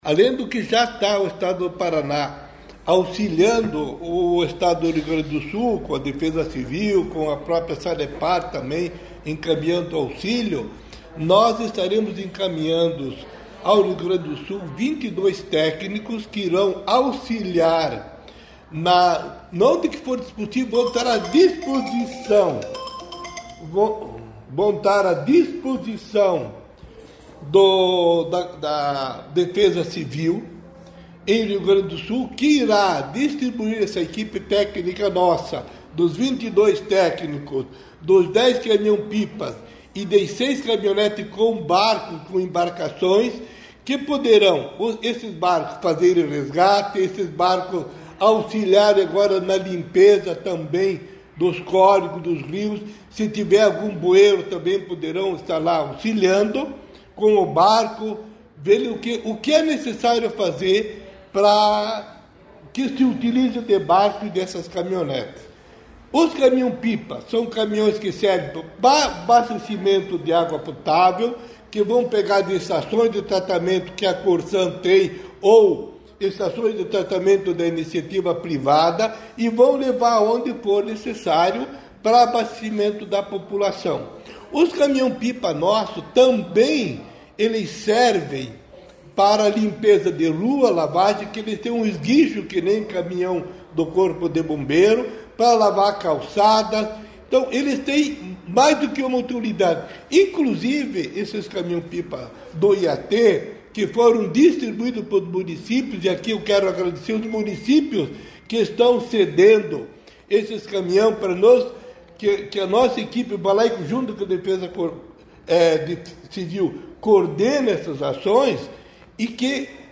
Sonora do diretor-presidente do IAT, José Luiz Scroccaro, sobre o envio de mais ajuda ao Rio Grande do Sul nesta segunda-feira